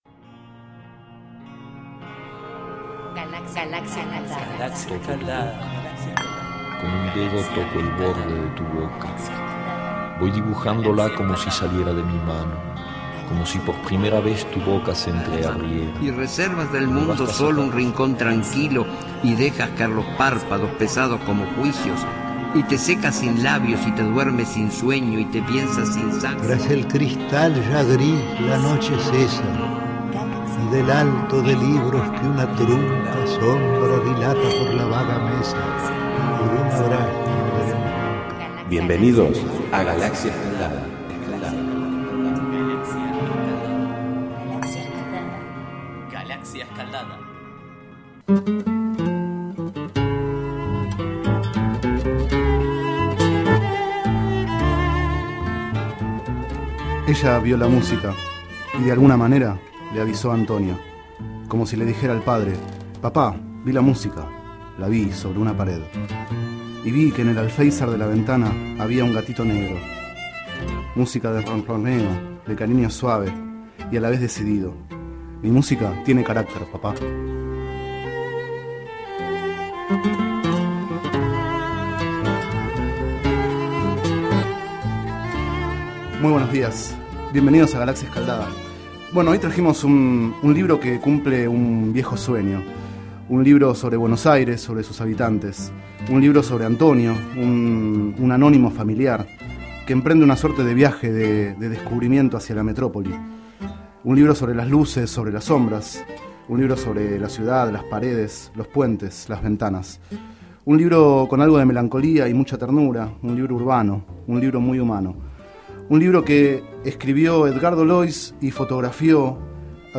Este es el 40º micro radial, emitido en los programas Enredados, de la Red de Cultura de Boedo, y En Ayunas, el mañanero de Boedo, por FMBoedo, realizado el 17 de diciembre de 2011, sobre el libro Guía de Buenos Aires (una ficción), de Eduardo Noriega y Edgardo Lois.